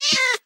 cat_hitt1.ogg